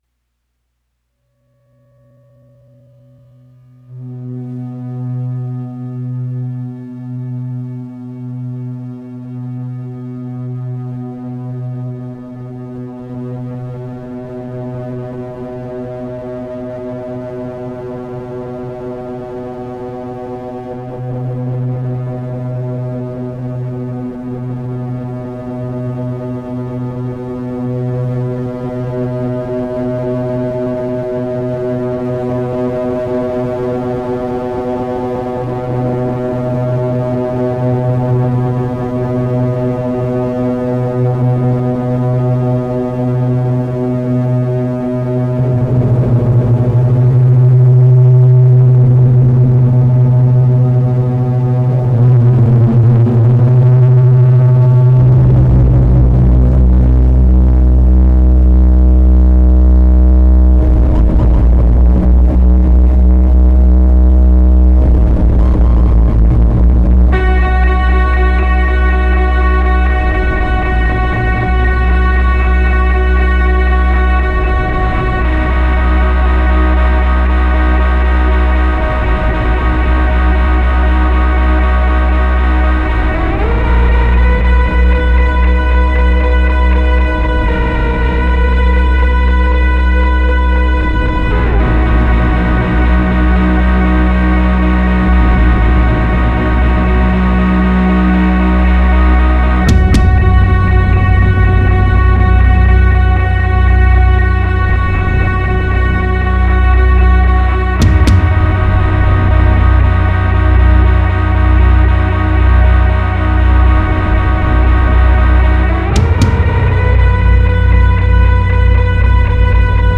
des titres tout aussi épiques